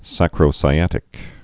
(săkrō-sī-ătĭk, sākrō-)